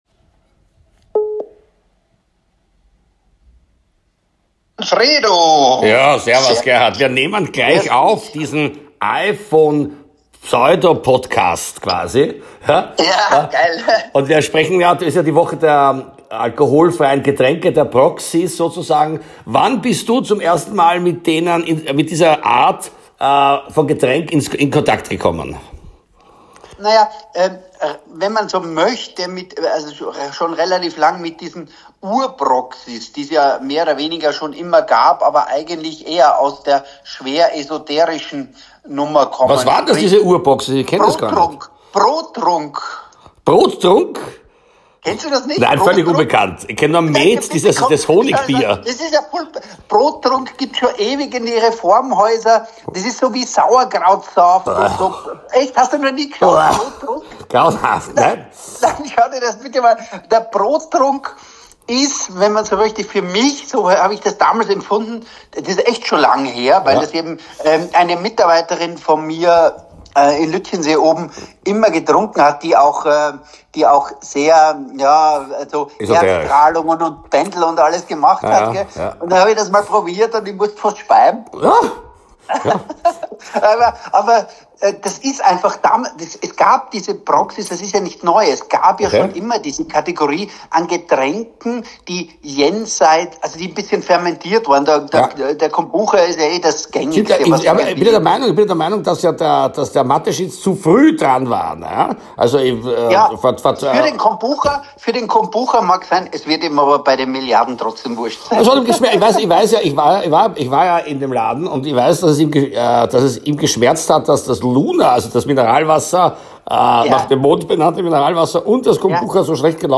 Deswegen ein neuer Telefon-Pseudo-Podcast, aufgenommen mit dem 6 Jahre alten I-Phone des monetär verarmten “Scheffredakteurs”.